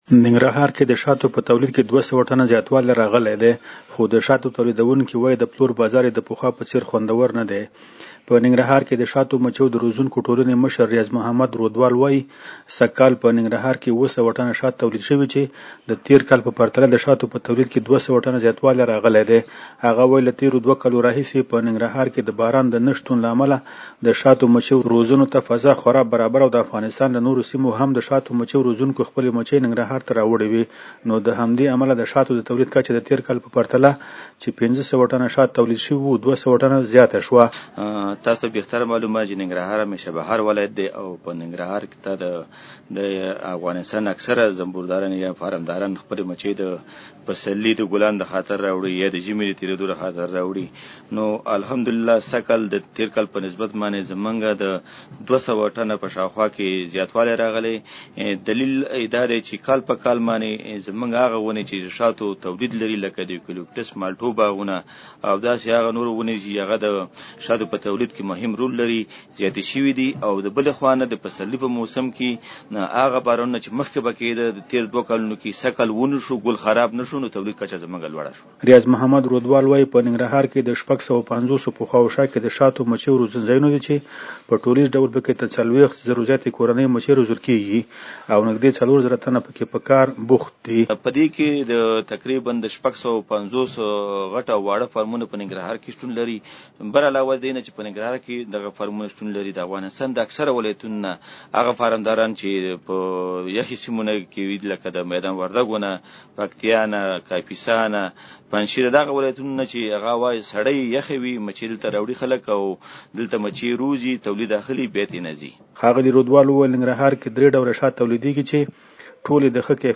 د افغانستان په ننګرهار ولایت کښې د شاتو په تولید کښې د ۲۰۰ ټنه زیاتوالي په هکله رپورټ